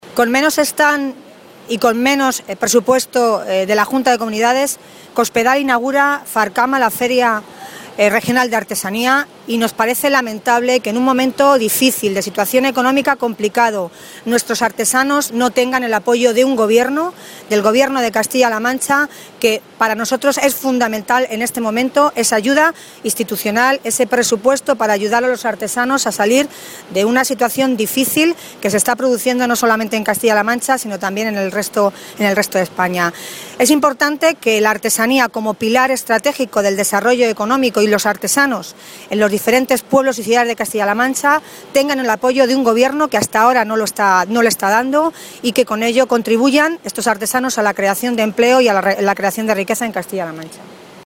Tolón, que realizaba estas declaraciones durante la inauguración hoy de la Feria de Artesanía de Castilla-La Mancha (Farcama) a la que asistió junto a otros responsables del Grupo Socialista, lamentó que este sea el segundo recorte importante que se produce a este sector, ya que en las cuentas públicas de este año ya se rebajaron un 50% estas partidas con respecto al 2011.
Cortes de audio de la rueda de prensa